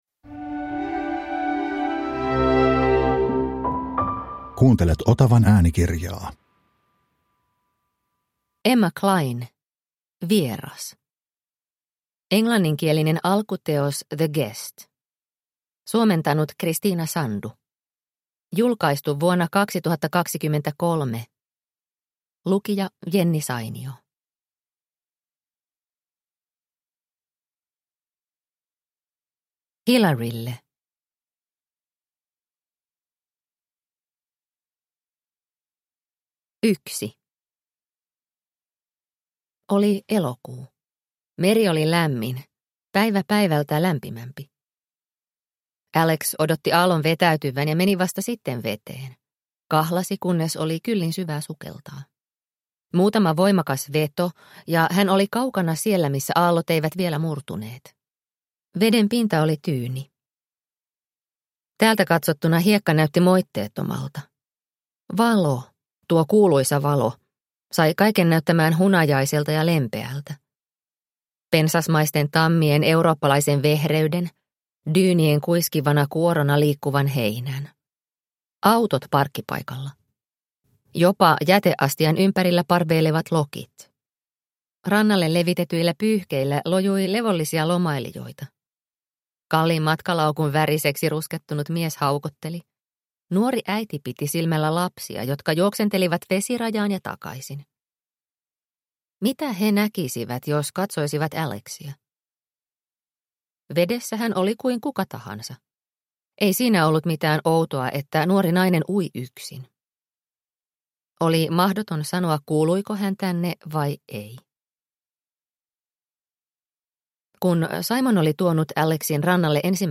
Vieras – Ljudbok – Laddas ner